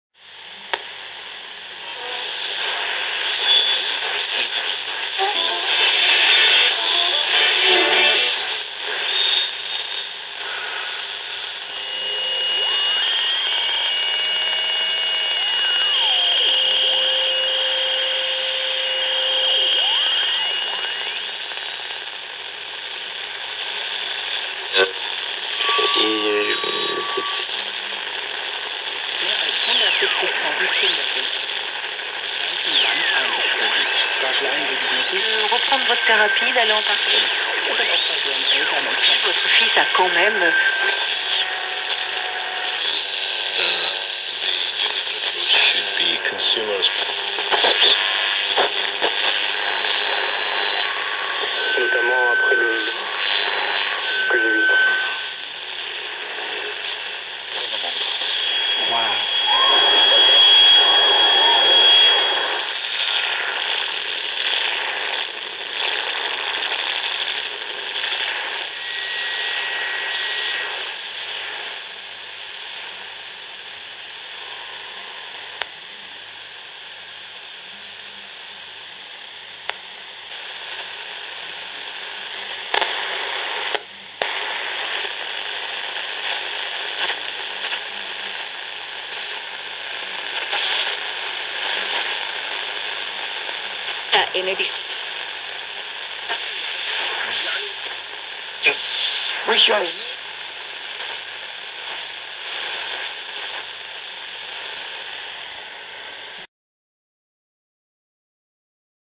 Radio Tuning Sound Effect.mp3